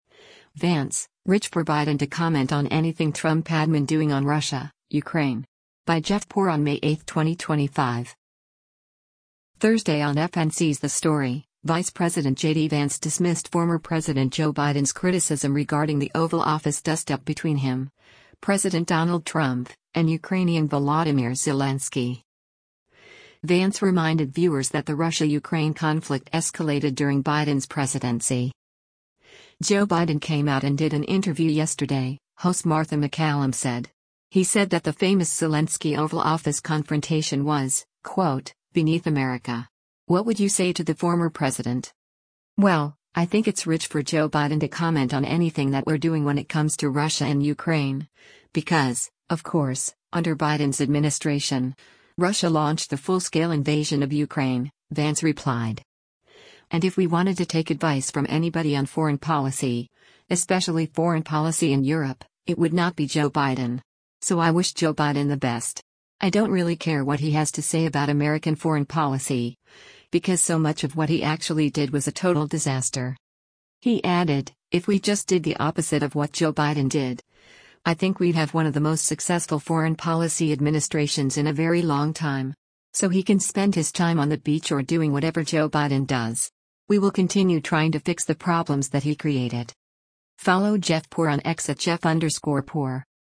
Thursday on FNC’s “The Story,” Vice President JD Vance dismissed former President Joe Biden’s criticism regarding the Oval Office dustup between him, President Donald Trump, and Ukrainian Volodymyr Zelensky.